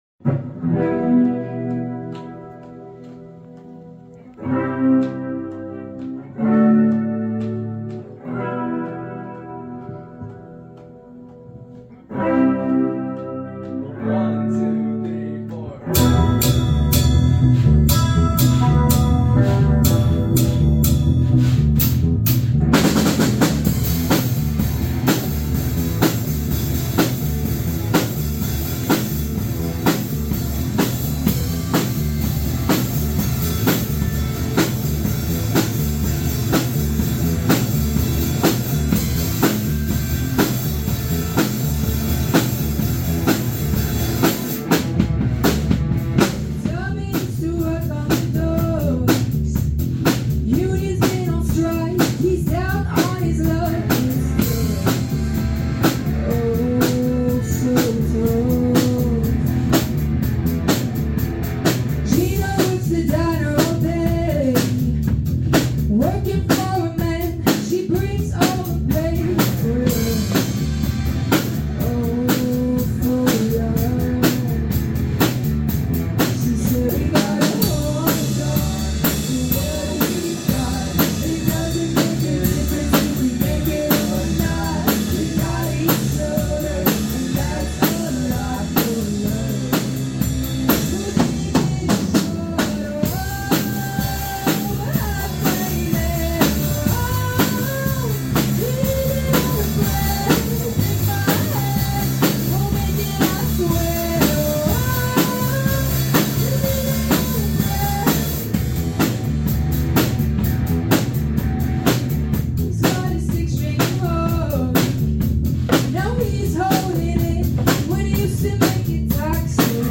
we are a 5 piece rock band
Cover